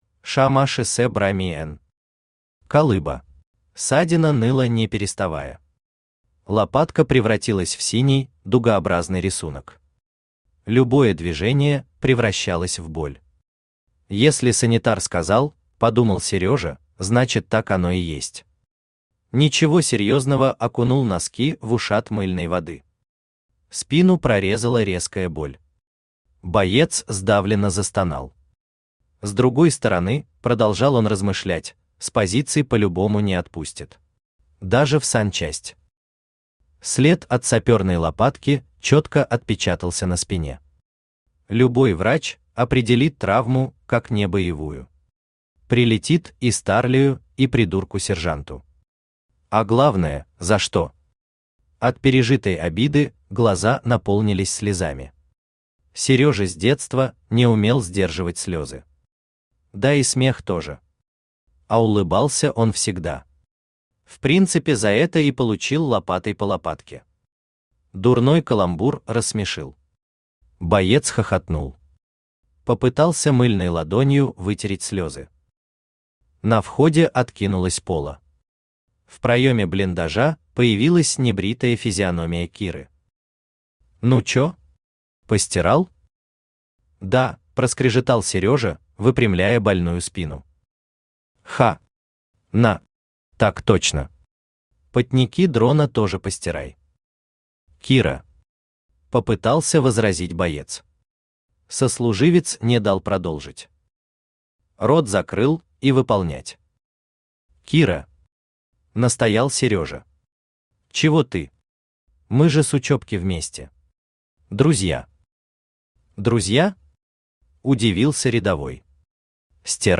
Аудиокнига Колыба | Библиотека аудиокниг
Aудиокнига Колыба Автор ШаМаШ БраМиН Читает аудиокнигу Авточтец ЛитРес.